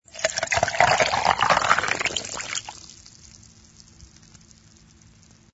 sfx_drinks_pouring02.wav